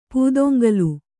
♪ pūdoŋgalu